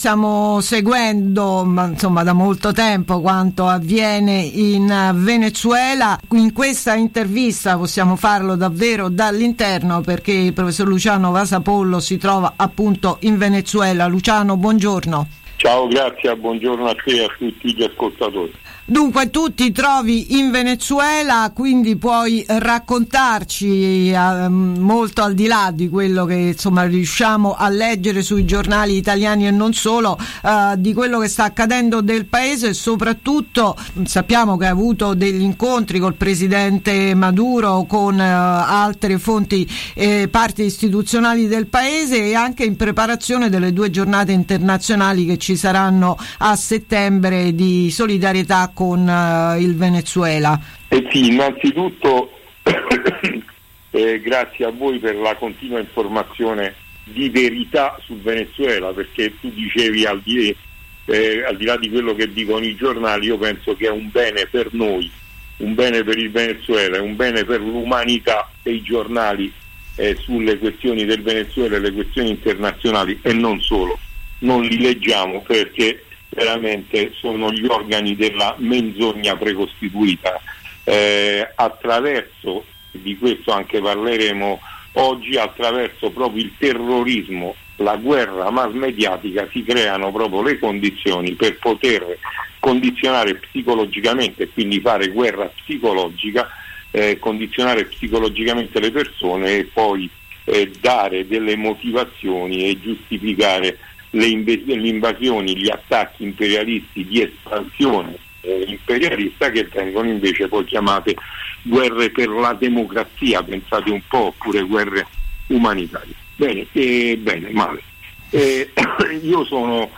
In diretta dal Venezuela: intervista